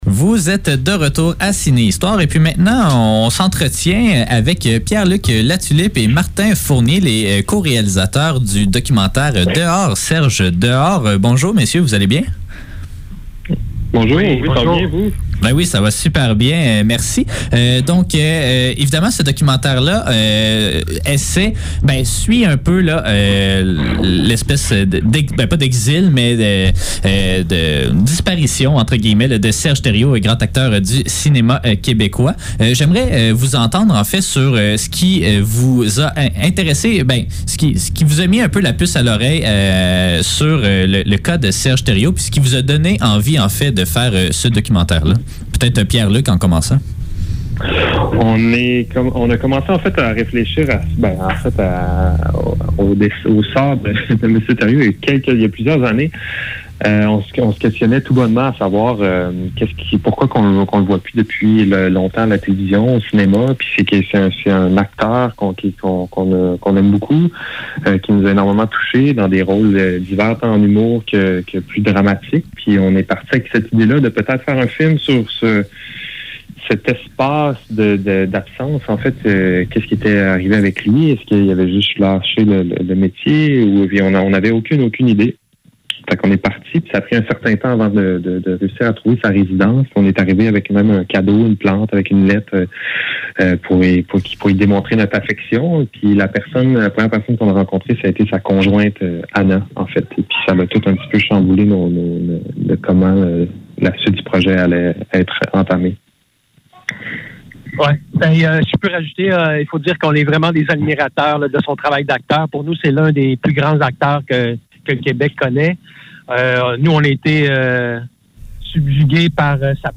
Ciné-histoire - Entrevue